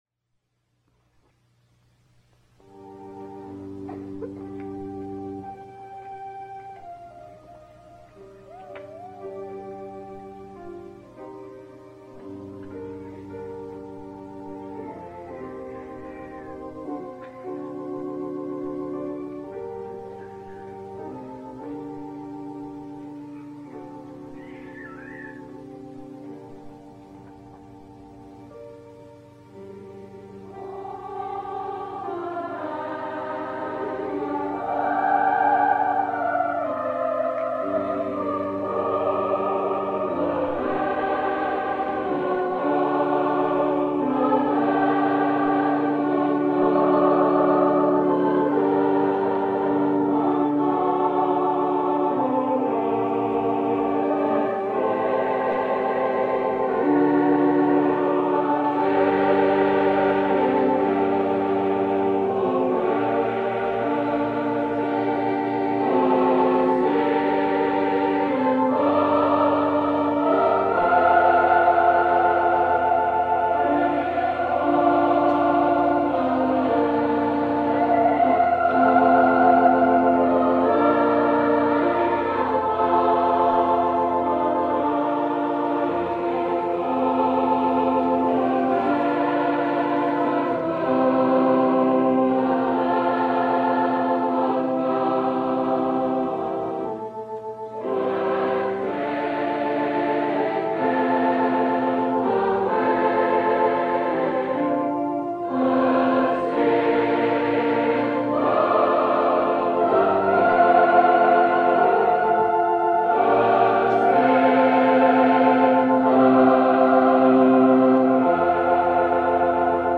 Handel's Messiah - Bethany Peniel College Oratorio Chorus (1953)
Handel's Messiah presented by the BPC Christmas Chorus, including members of Bethany First Church of the Nazarene
Recorded at Bethany First Church of the Nazarene, now Herrick Auditorium on Sunday, 13 Dec. 1953.
Soprano
Contralto
Tenor